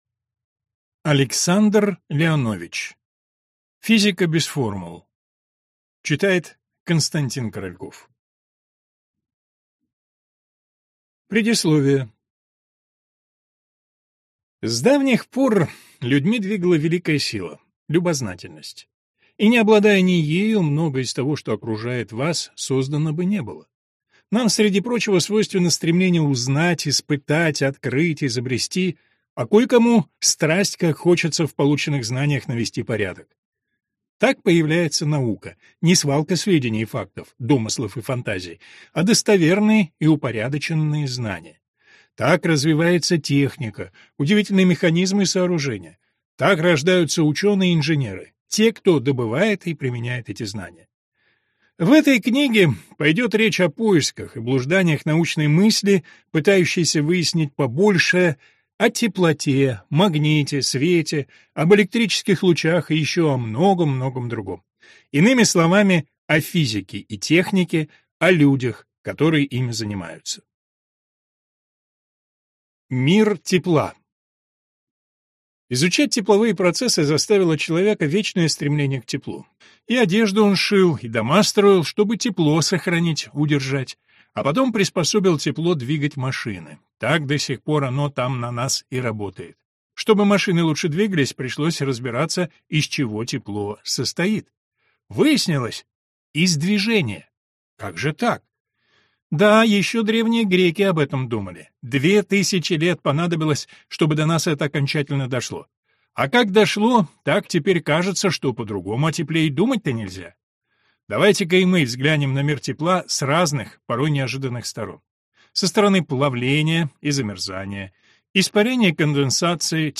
Аудиокнига Физика без формул | Библиотека аудиокниг